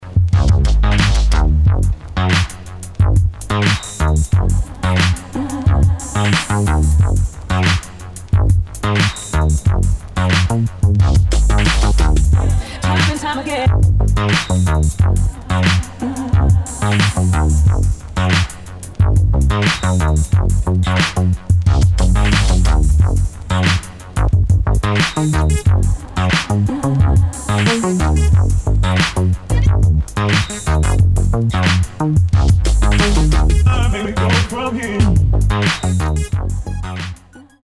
[ DOWNBEAT / BASS / EXPERIMENTAL ]